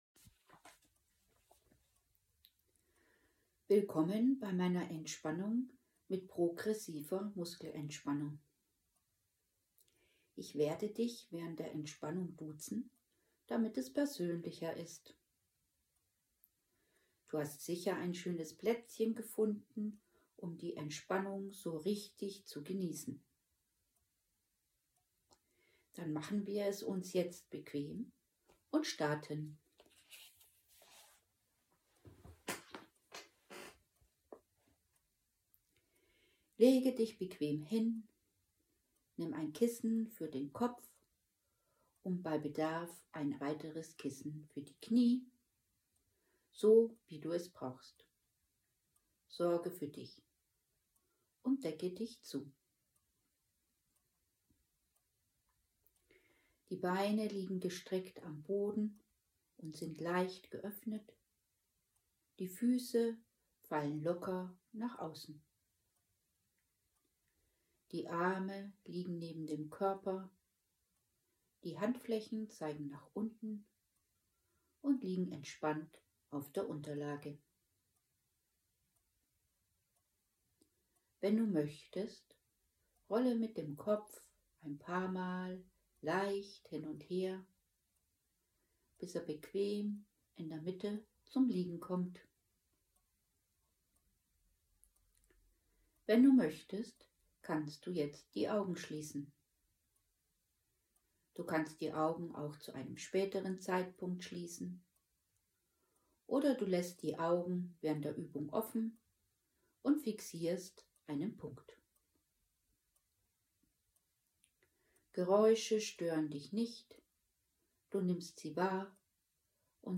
Der folgende Link führt Sie durch eine von mir geführte PM Sitzung: